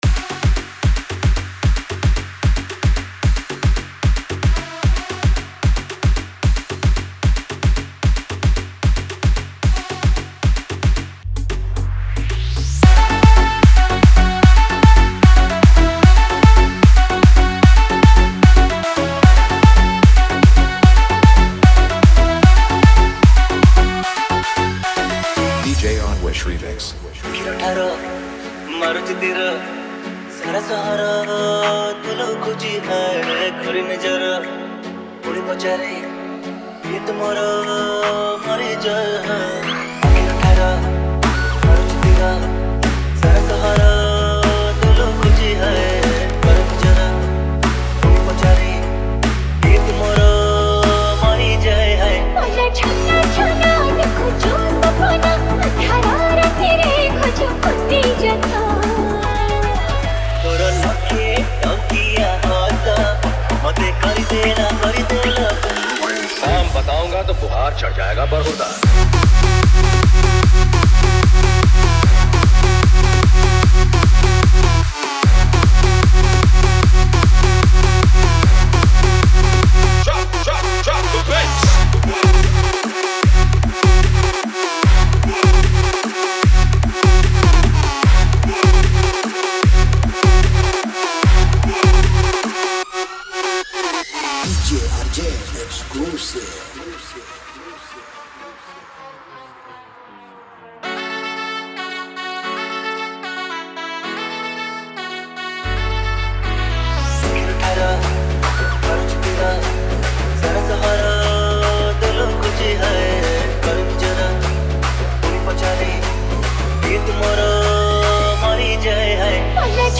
Single Dj Song Collection 2022